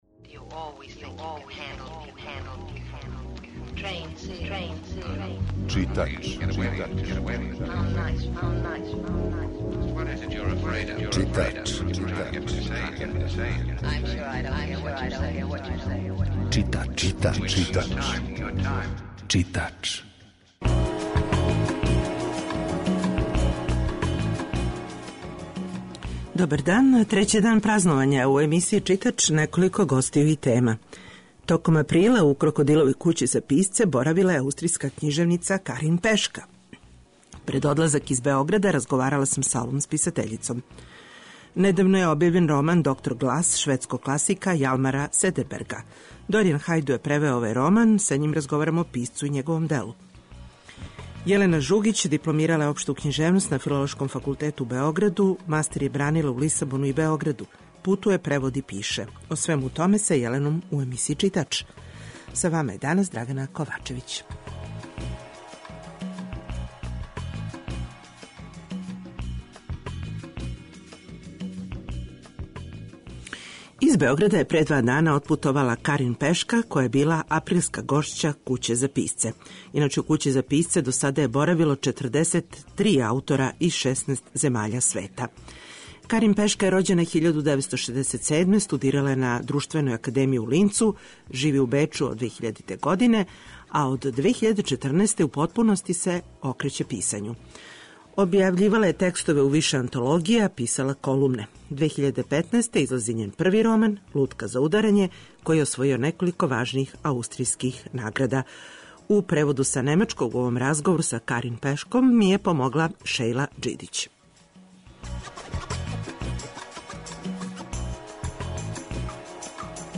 И у овој емисији разговарамо са писцима, преводиоцима, препоручујемо књиге
У данашњој емисији "Читач", чућете разговор са ауторком пред њен повратак у Аустрију.